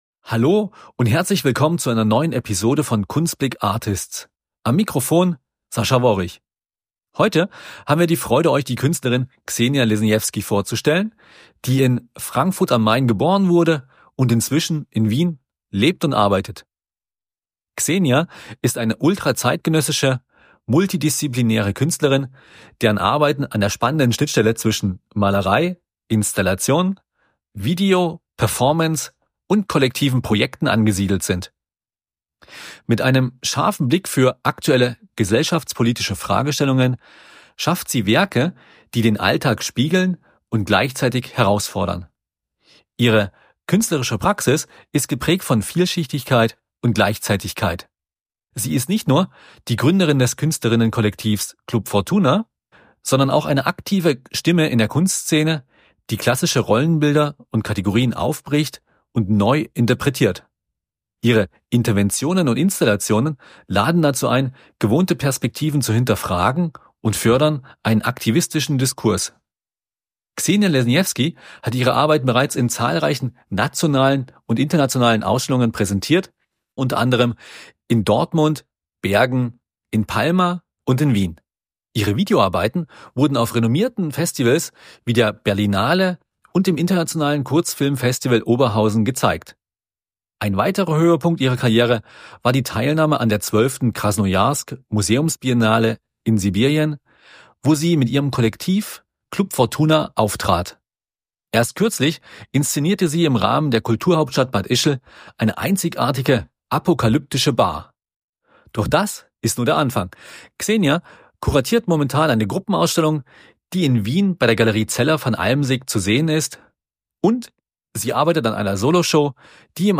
Freut euch also auf ein spannendes Gespräch